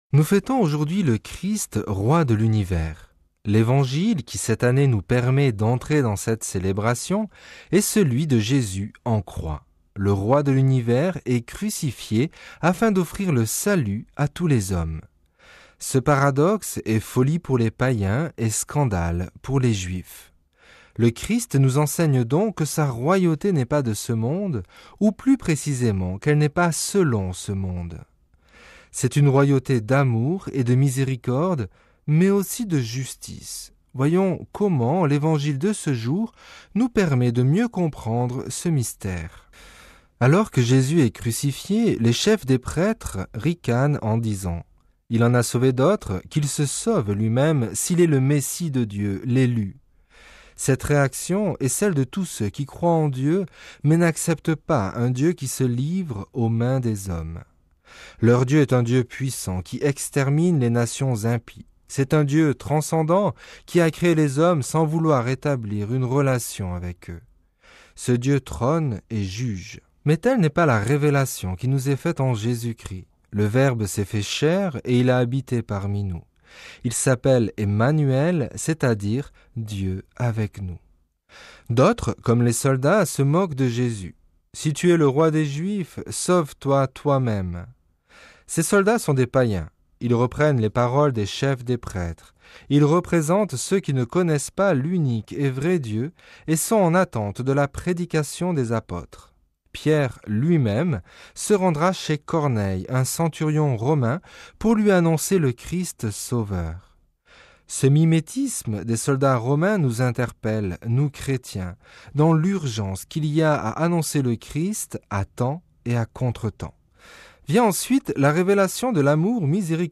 Commentaire de l'Evangile du dimanche 24 novembre